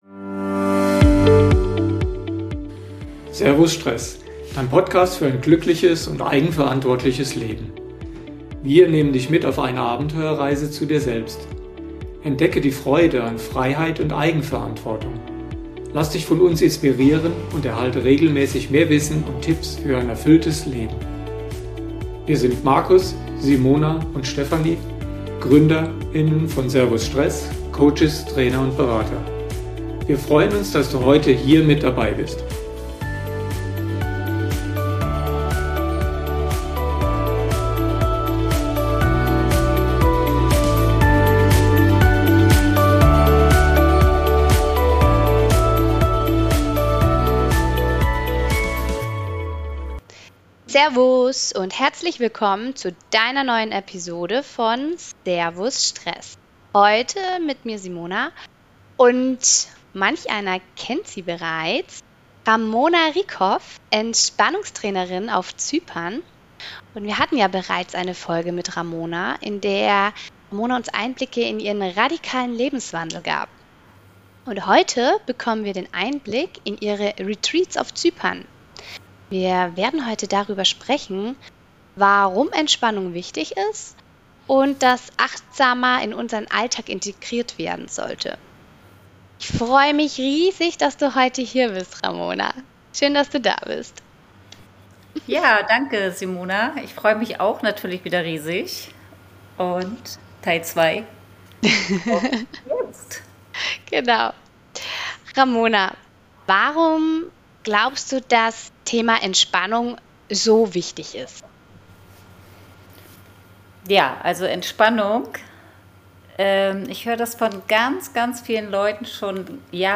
Beschreibung vor 1 Jahr In dieser Episode sprechen wir mit einer erfahrenen Entspannungstrainerin über die Bedeutung von Entspannung und warum Retreats mehr bieten als ein normaler Urlaub. Sie erklärt, wie ihre Retreats aufgebaut sind, welche Techniken dabei helfen, echten Stress abzubauen und welche Veränderungen Teilnehmer durchlaufen.